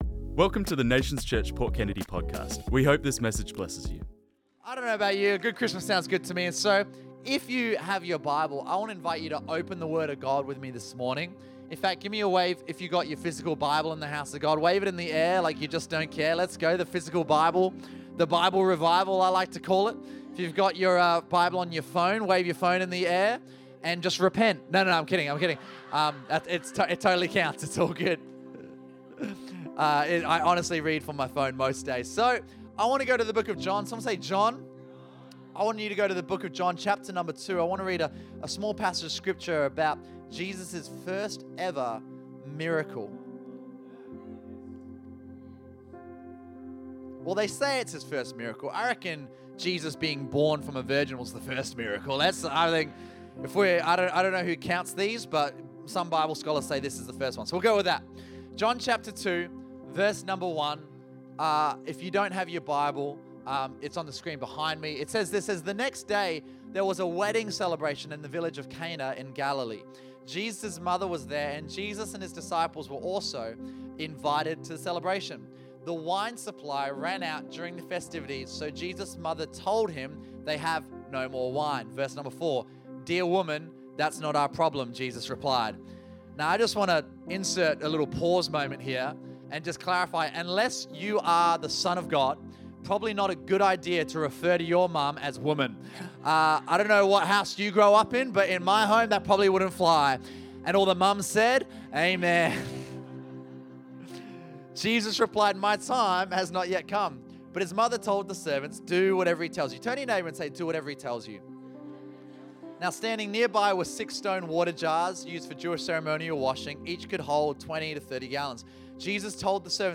This message was preached on Sunday 22nd December 2024, by guest speaker